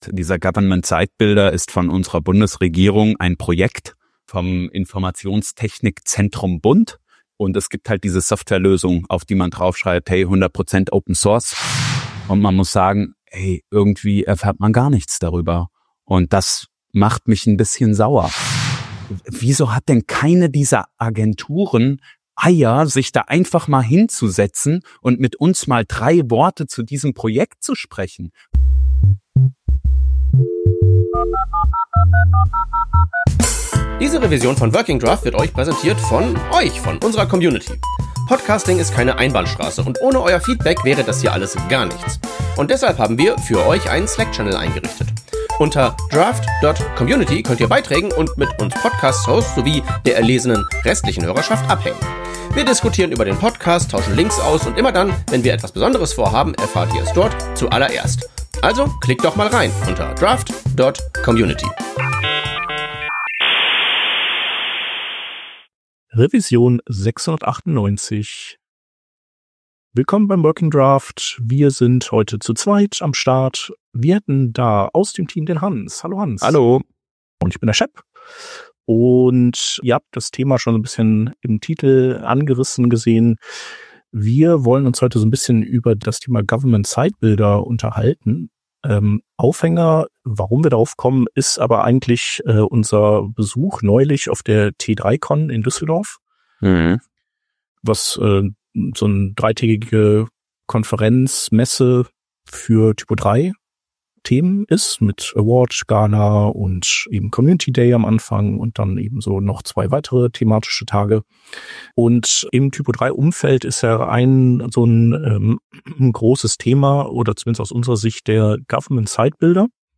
In dieser Folge sprechen wir zu zweit über unsere Eindrücke rund um den Government Site Builder (GSB) – ausgelöst durch unseren Besuch auf der T3CON in Düsseldorf.
Herausgekommen ist stattdessen eine kurze, leicht rantige Bestandsaufnahme darüber, wie schwer es ist, überhaupt belastbare Informationen oder Gesprächspartner:innen zum GSB zu finden.